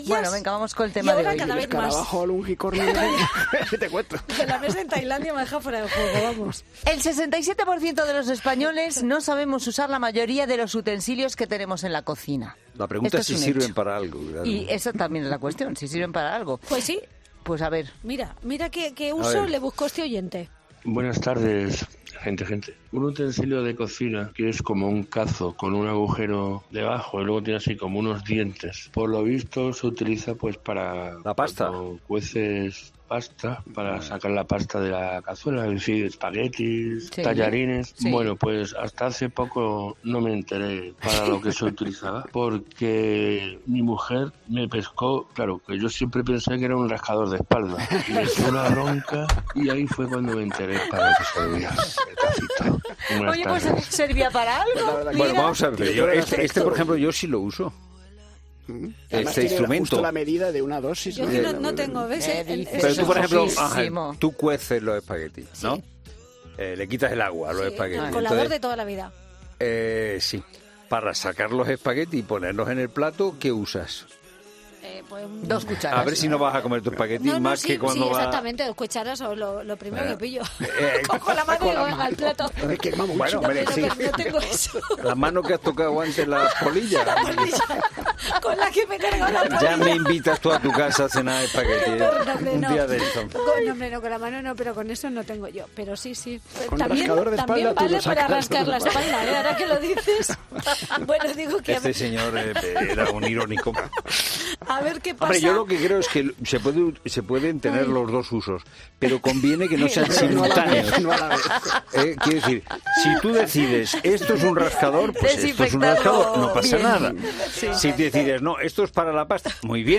Un asunto que hemos abordado este jueves en 'La Tarde' y hemos querido escuchar las historias de los oyentes.